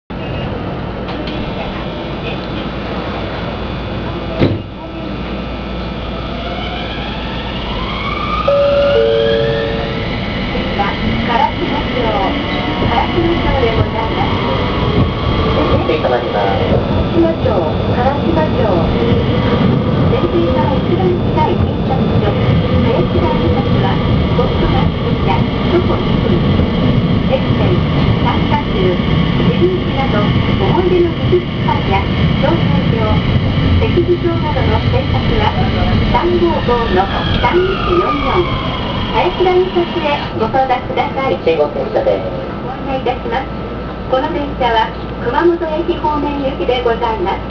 〜車両の音〜
・0800形走行音
【２・３系統】熊本城前〜辛島町（…の、途中で信号に引っかかるまで）（48秒：259KB）
車内放送はバスなどでよく聞くパターン。走行音も標準化されたLRT車両で基本となるタイプの三菱IGBTで、これといって目新しい特徴はありません。